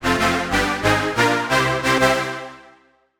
gameWin.mp3